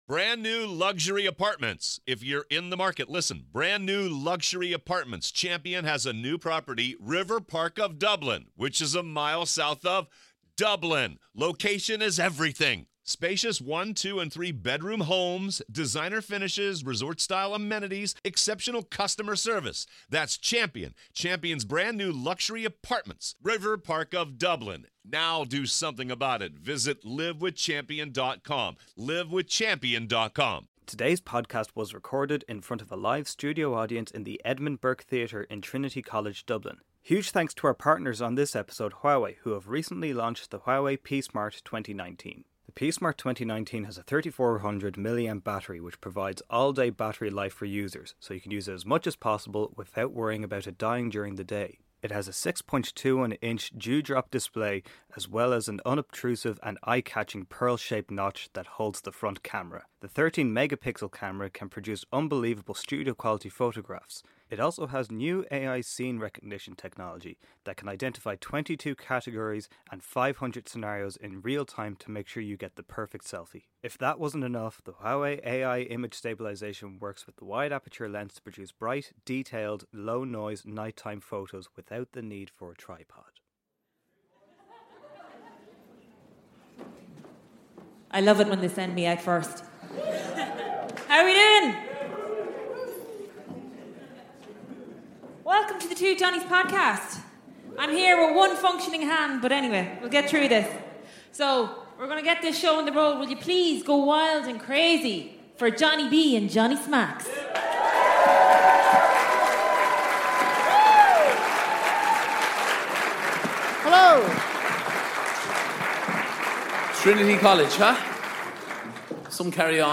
Ep.57: Live in Trinity
Ireland's favourite comedy duo tackle the big issues.This Week: The boys take on the Irish college system and C.A.O. while live in Dublin's Trinity college.